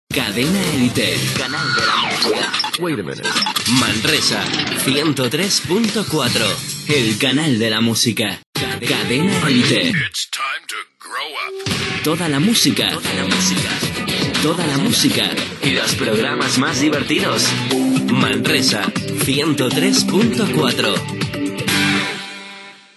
Indicatiu de l'emissora a Manresa.
FM